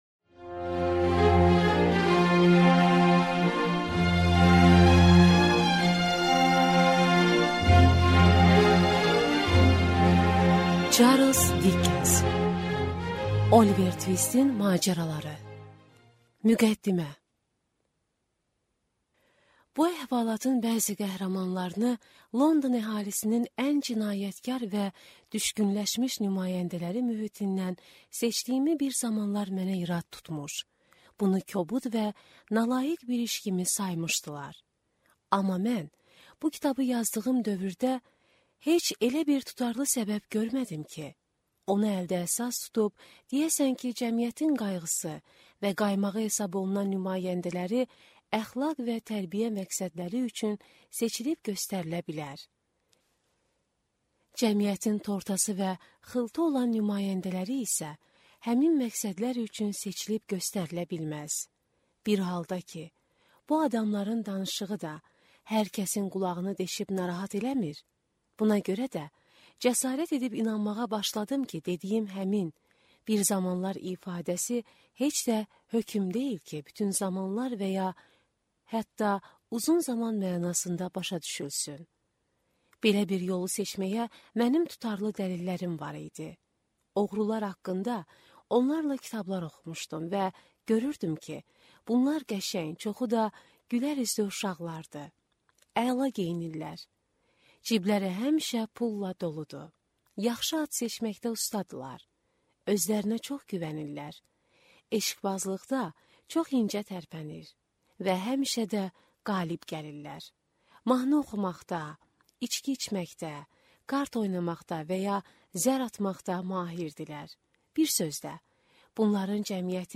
Аудиокнига Oliver Tvistin macəraları | Библиотека аудиокниг